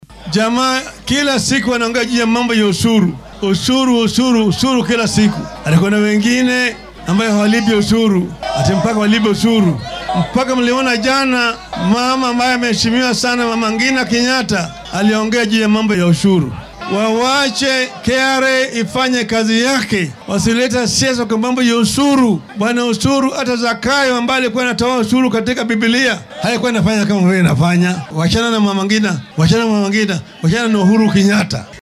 Hoggaamiyaha isbeheysiga mucaaradka ee Azimio La Umoja-One Kenya Raila Odinga oo shalay isku soo bax siyaasadeed ku qabtay deegaanka Kibra ee ismaamulka Nairobi ayaa baaq u diray madaxweynaha dalka.